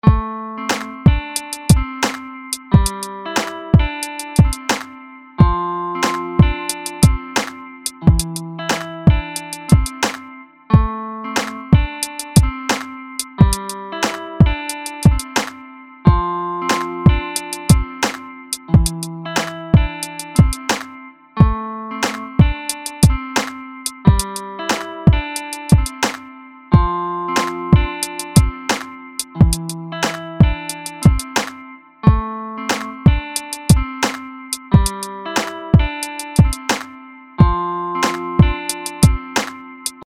Es handelt sich um Rap und ich bin mir nicht sicher ob es mit das Beste oder das Schlechteste ist was ich so geschrieben habe.
Das ist ja jetzt nur eine Gitarre und ein kurz zusammengebastelter Drumloop.